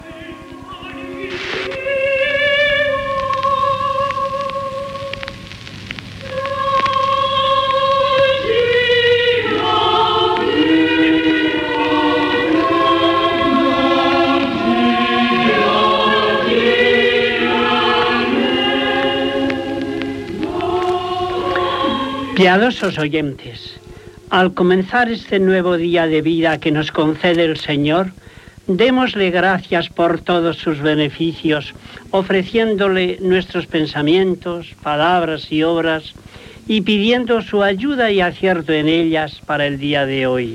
Música i introducció de l'espai.
Religió
Extret de Crònica Sentimental de Ràdio Barcelona emesa el dia 15 d'octubre de 1994.